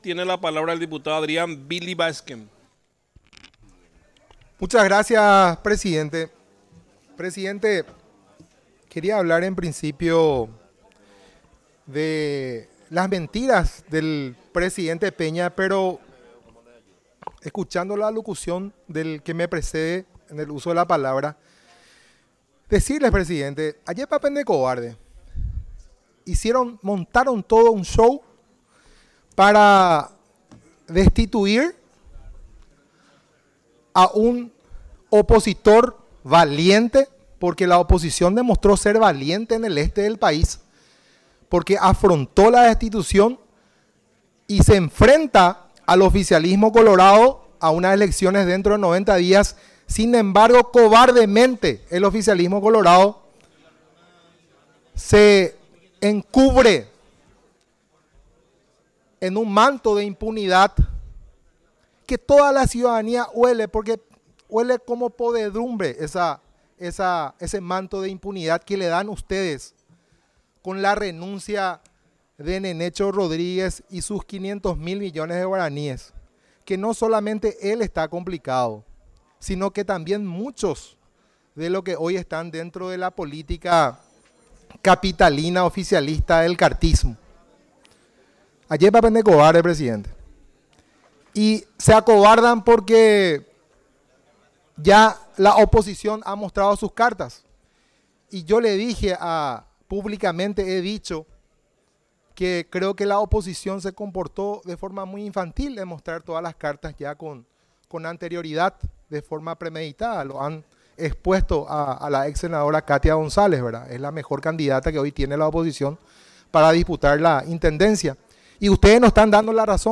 Sesión Extraordinaria, 26 de agosto de 2025
Exposiciones verbales y escritas
05 – Dip Yamil Esgaib